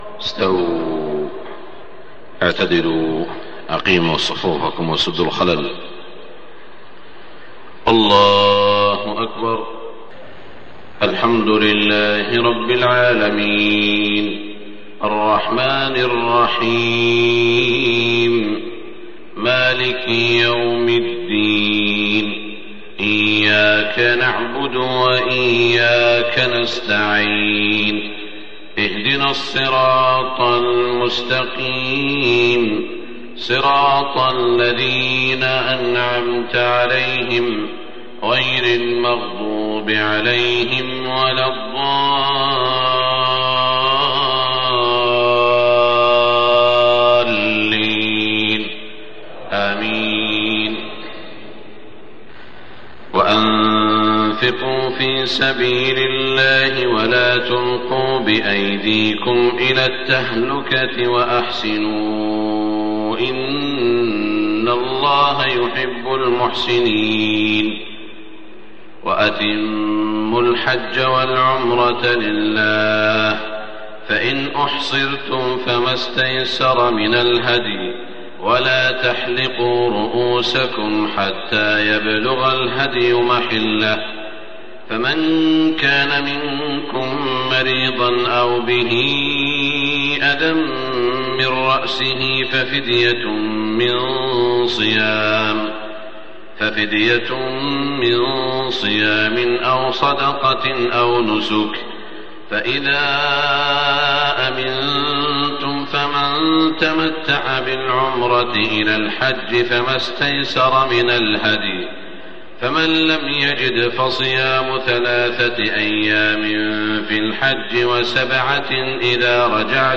صلاة الفجر في شهر ذي الحجة عام ١٤٢٤ من سورة البقرة > 1424 🕋 > الفروض - تلاوات الحرمين